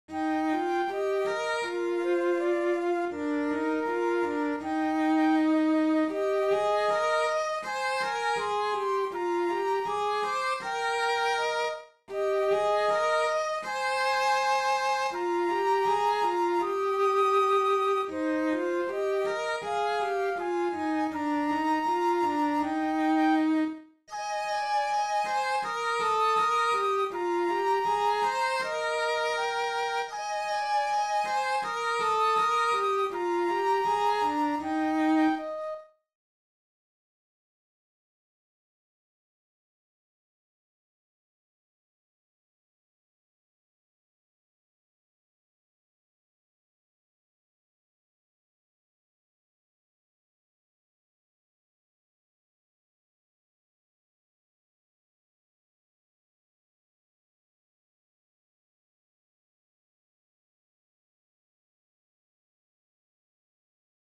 Kynnyksella-sello-ja-huilu.mp3